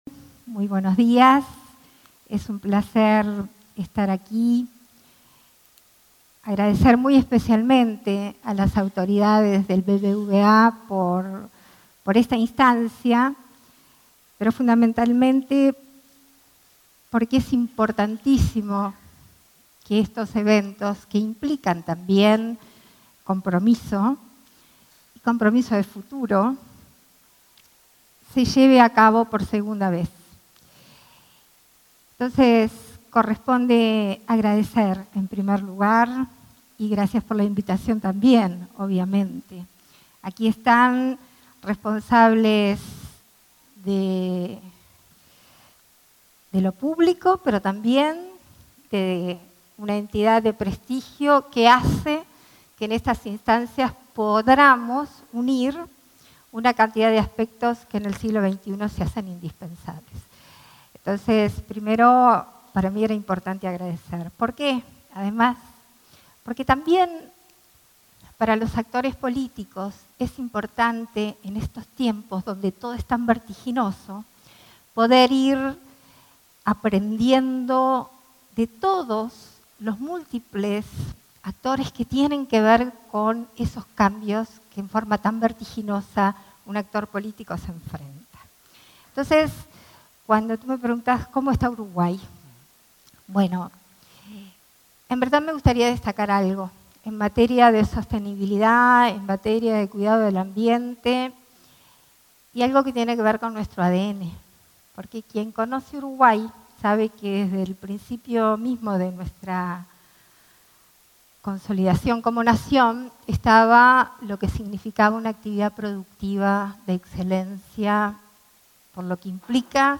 Palabras de la presidenta de la República en ejercicio, Beatriz Argimón
Palabras de la presidenta de la República en ejercicio, Beatriz Argimón 30/05/2024 Compartir Facebook X Copiar enlace WhatsApp LinkedIn En el marco del II Foro de Sostenibilidad, Desafíos Uruguay 2030, este 30 de junio, se expresó la presidenta de la República en ejercicio, Beatriz Argimón.
oratoria.mp3